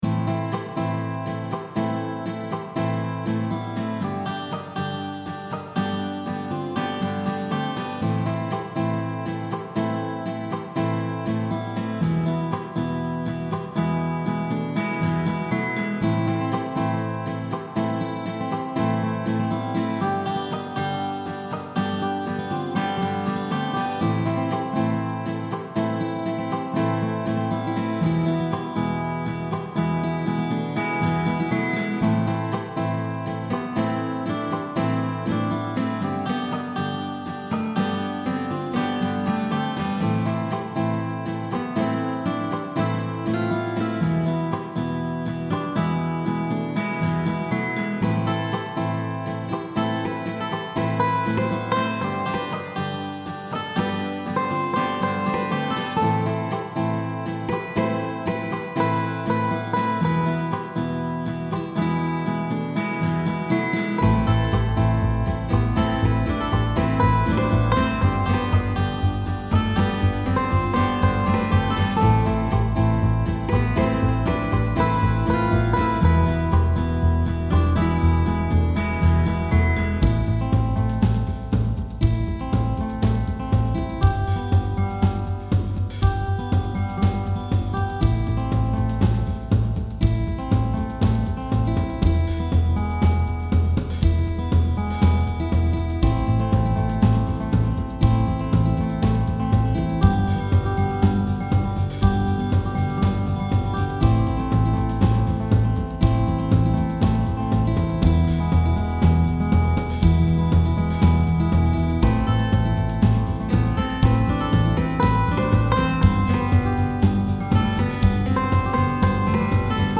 rough versions of two of the tracks
an instrumental piece